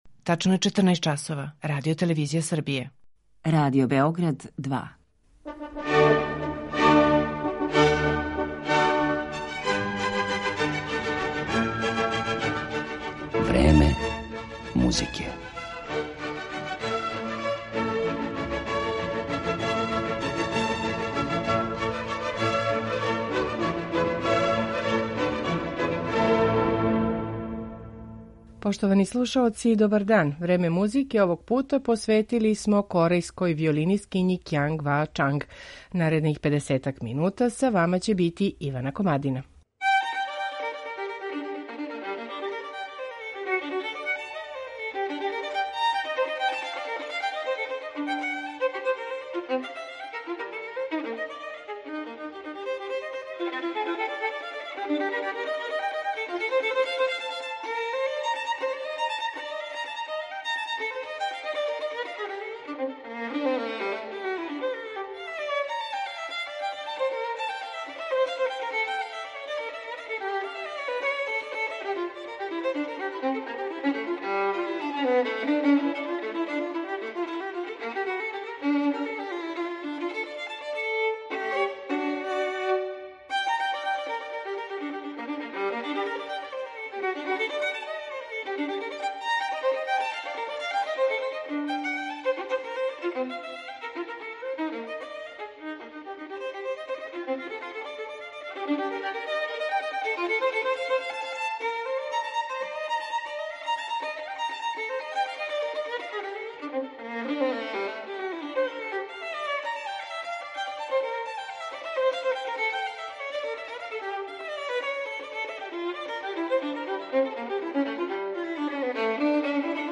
Кјанг Ва Чанг, виолина